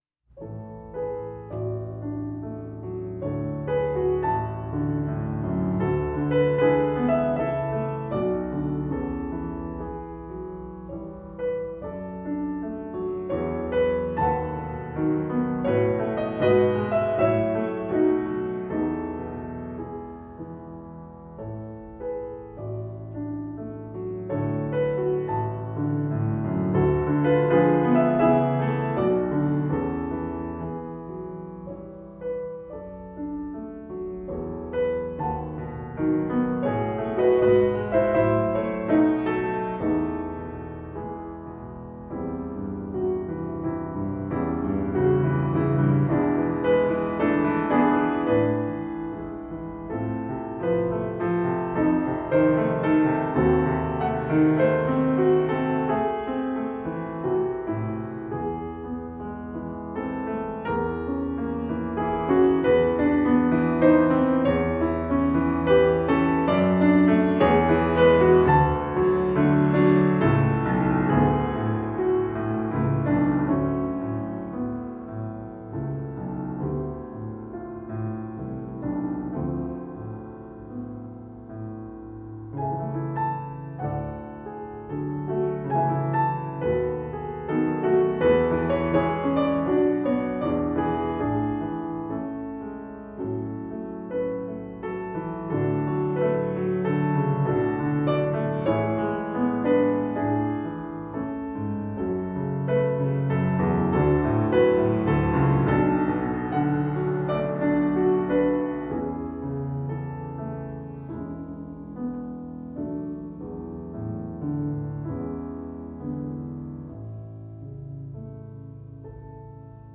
piano audio only
Postlude  Johannes Brahms, Intermezzo in A major, Op. 118 No. 2
piano